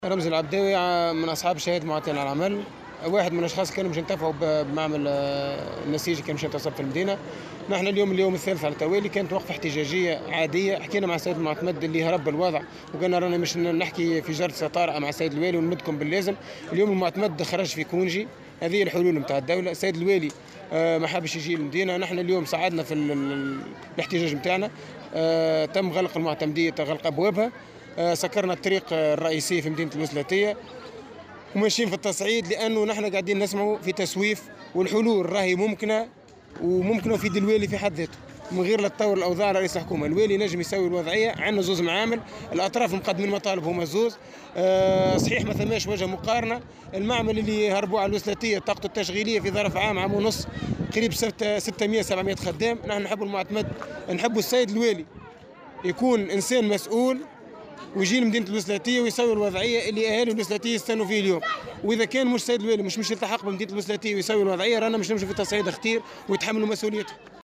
أحد المحتجين